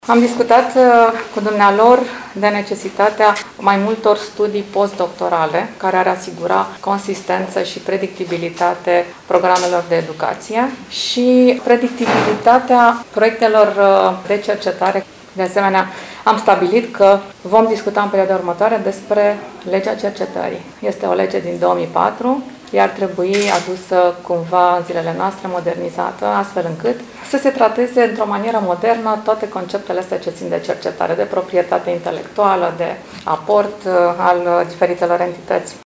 Președinta Senatului a declarat – referitor la discuțiile de la Universitate – că a rămas impresionată de dotările campusului universitar și de profesionalismul cadrelor didactice.